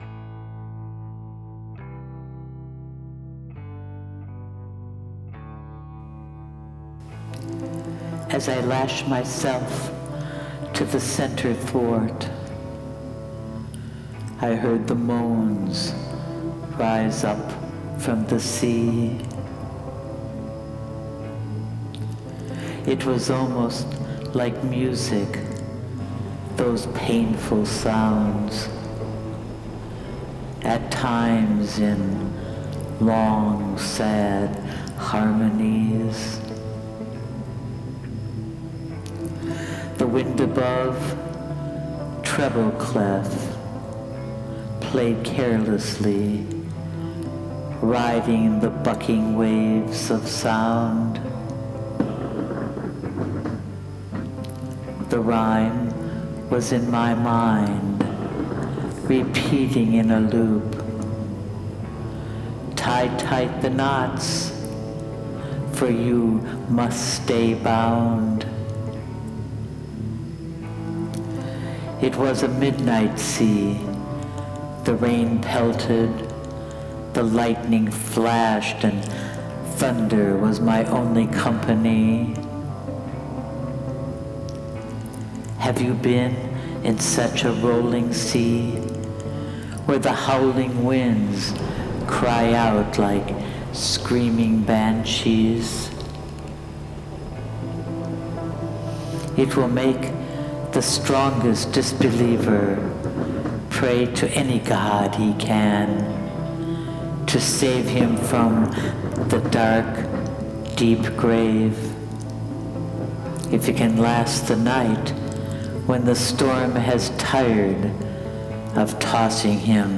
this is something a bit different… my spoken word to my soundtrack but not really music..more
storm-at-sea.wav